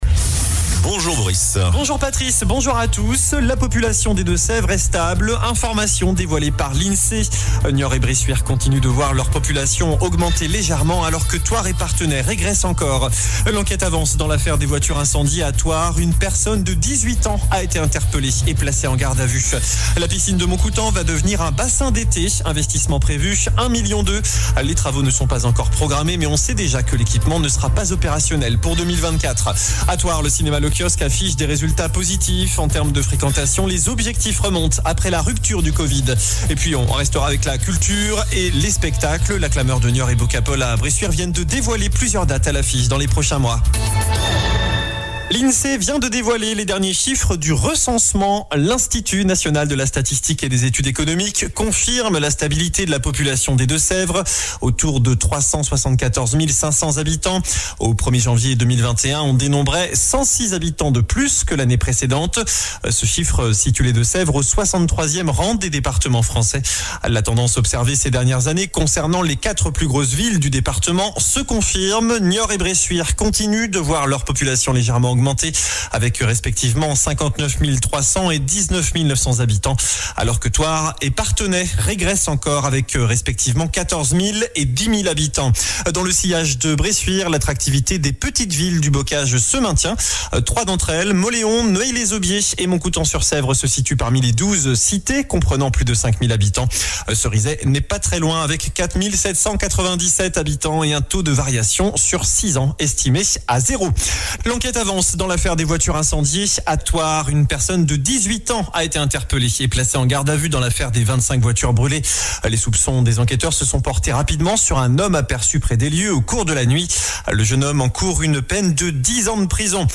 JOURNAL DU VENDREDI 29 DECEMBRE ( MIDI )